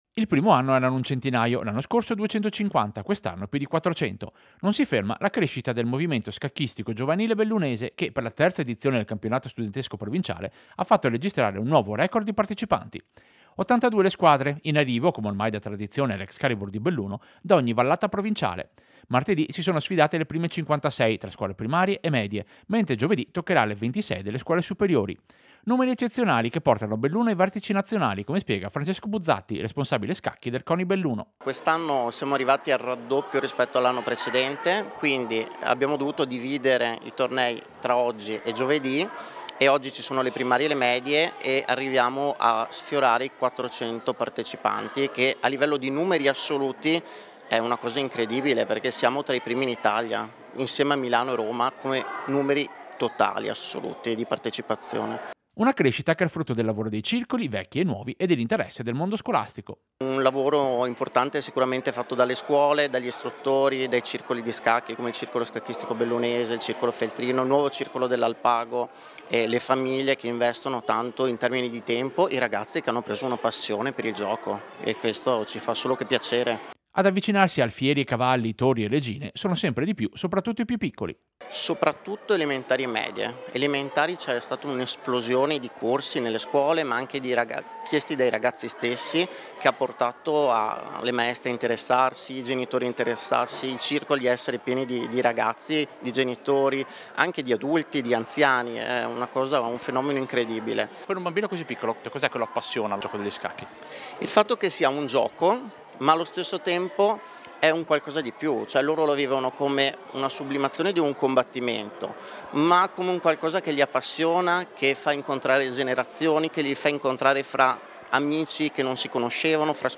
Servizio-Campionato-studentesco-scacchi-2026.mp3